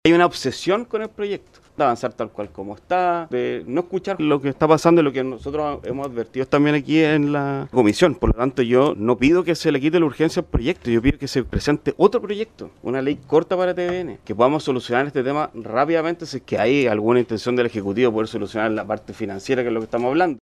Por su lado, el diputado del partido liberal, Alejandro Bernales, señaló que no es necesario quitar la urgencia al proyecto, sino retirarlo completamente y presentar una Ley Corta.